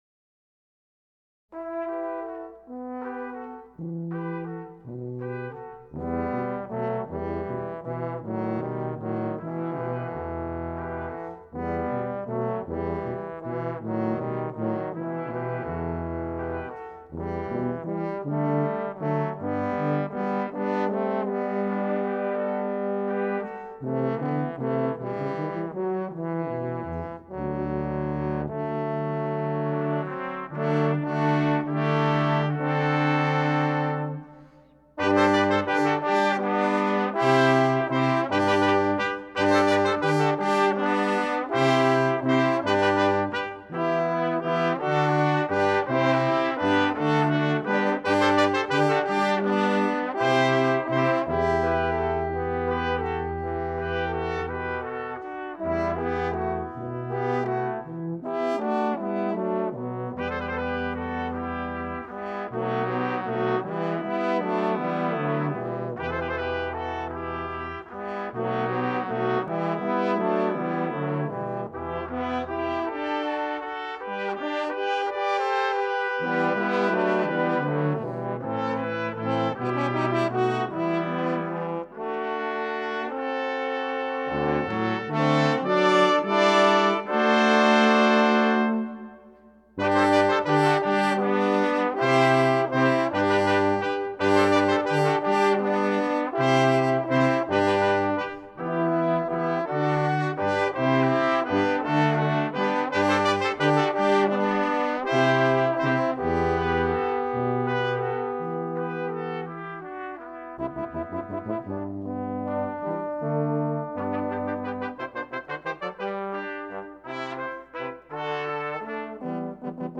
for Brass Quintet (2007)